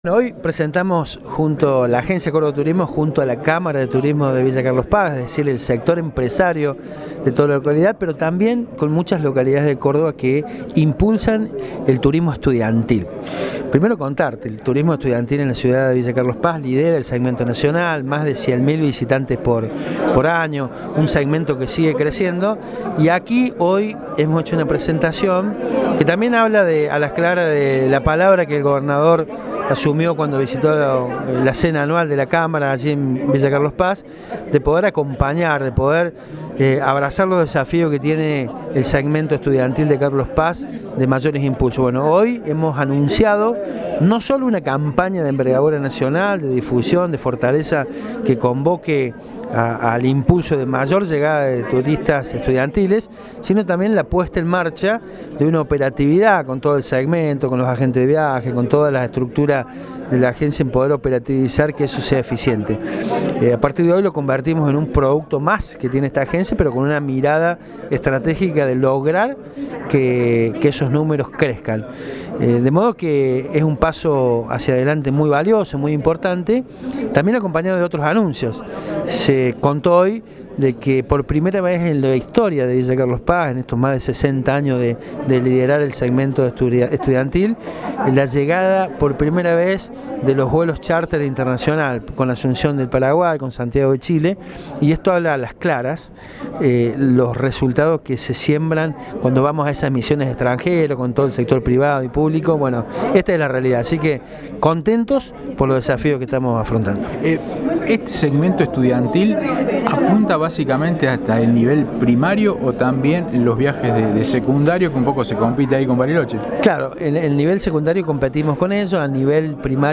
Audio: Darío Capitani (Presidente Agencia Córdoba Turismo).